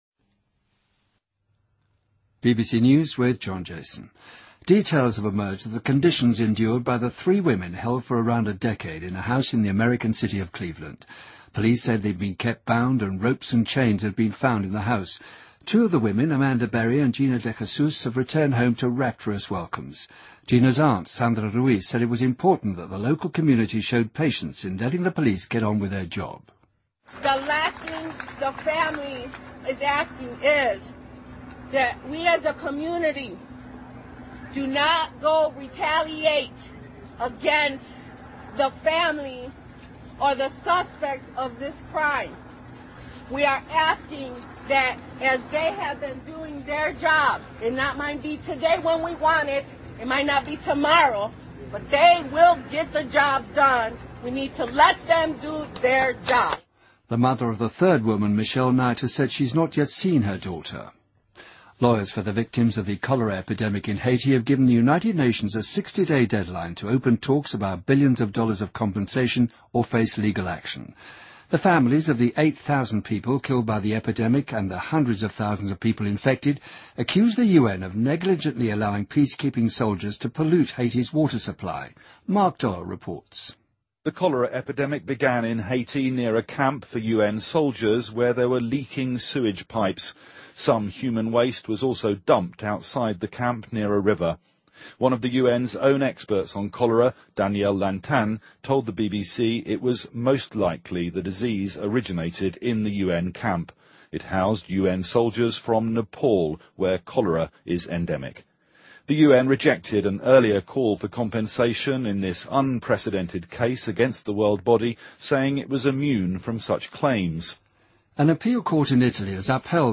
BBC news,2013-05-09